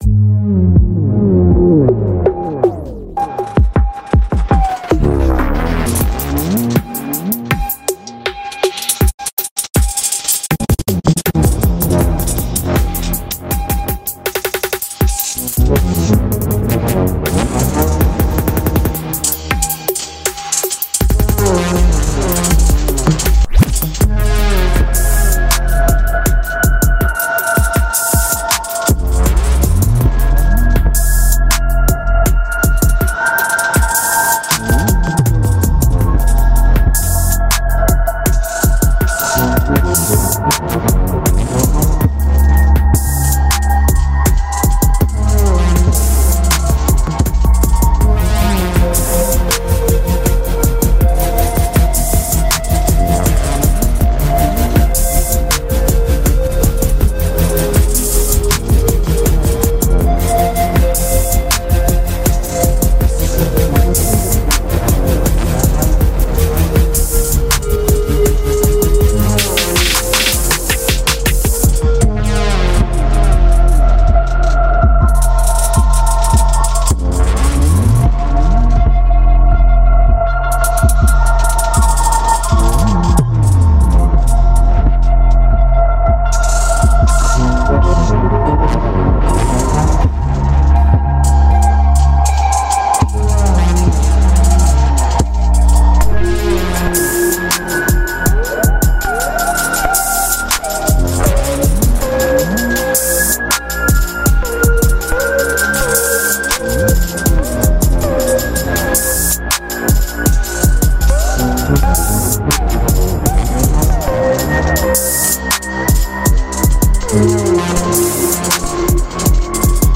An awesome beat check it sound effects free download